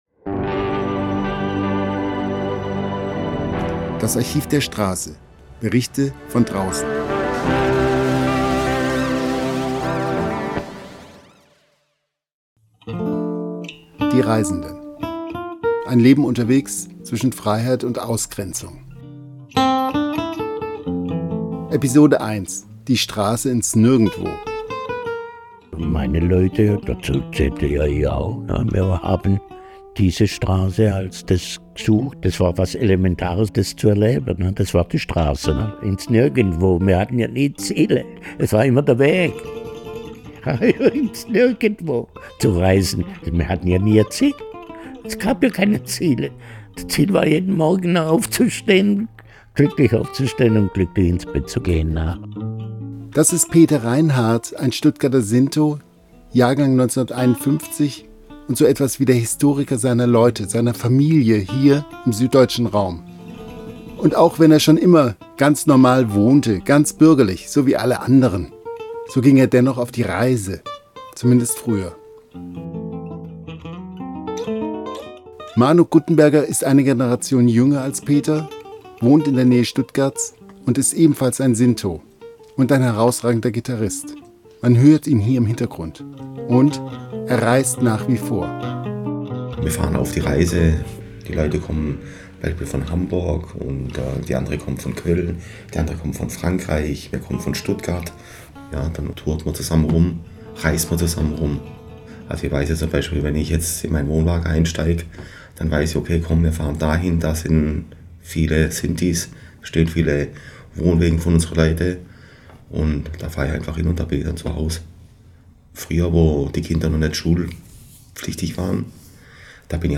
Die beiden Stuttgarter Sinti